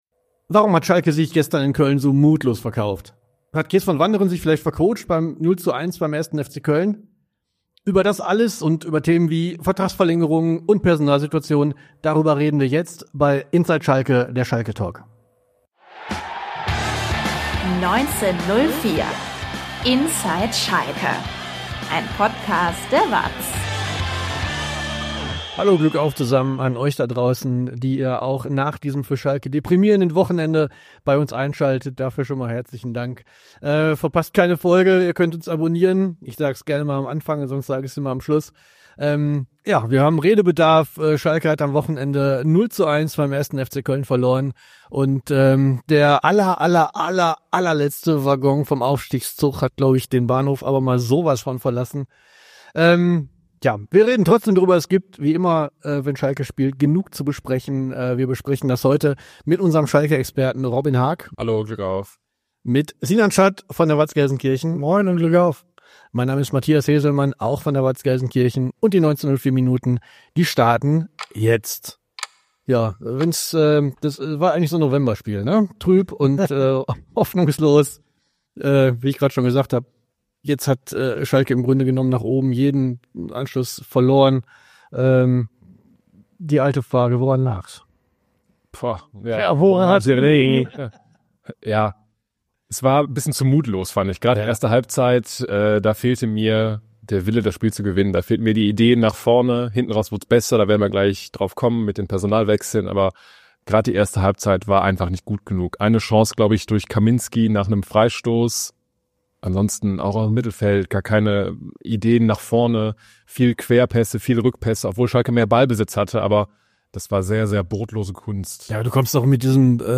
Ob Training, Spieltag oder Transferfenster: Die Analysen liefert ein Team aus Journalisten der WAZ und des RevierSports.